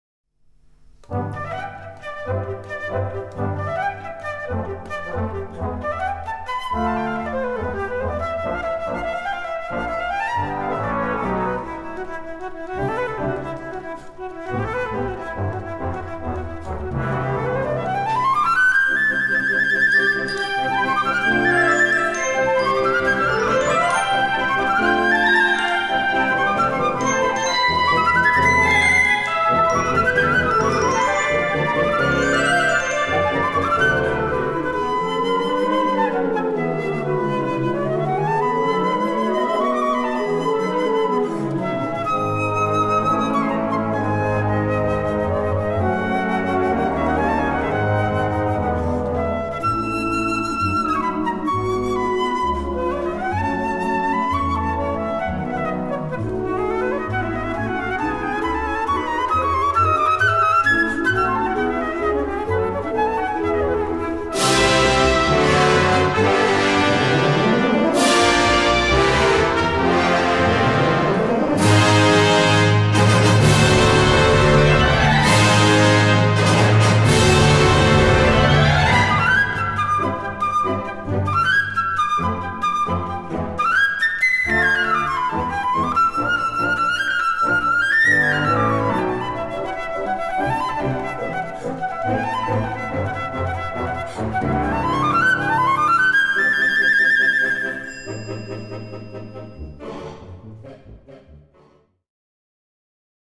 Gattung: Solo für Flöte und Blasorchester
20:00 Minuten Besetzung: Blasorchester Zu hören auf